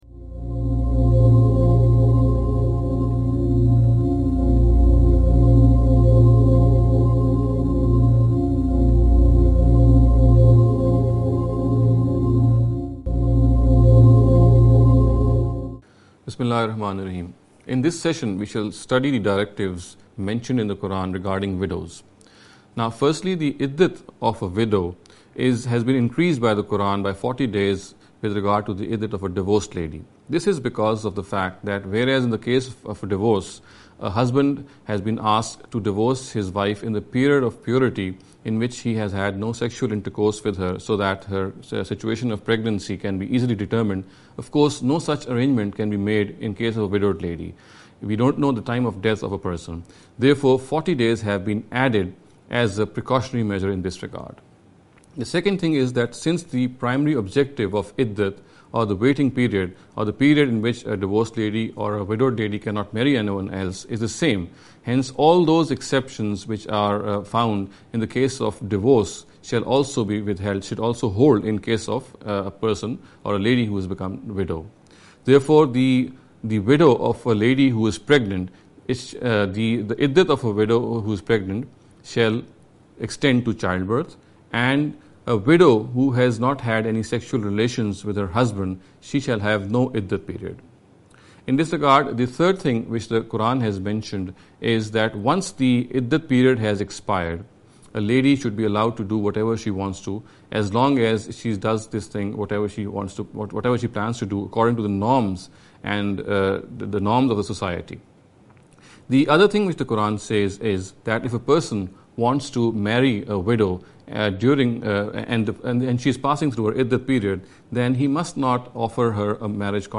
This lecture series will deal with some misconception regarding the Understanding The Qur’an.